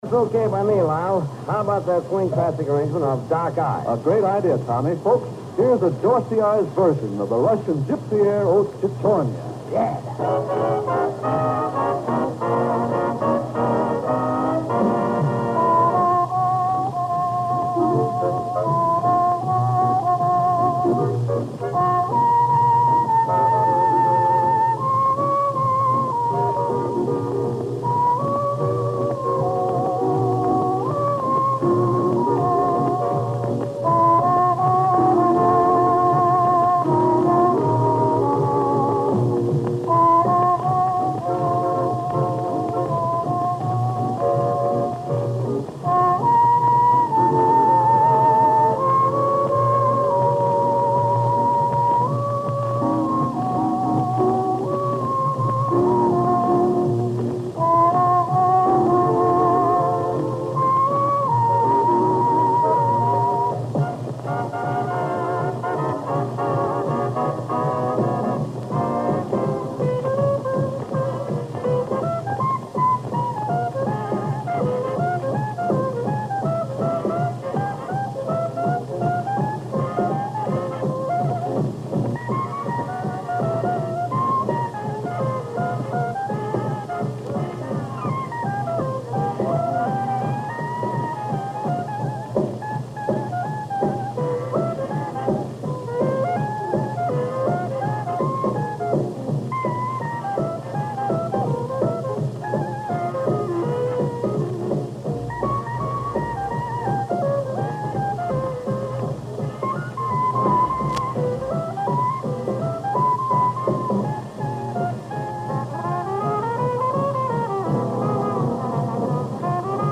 Live radio on acetate.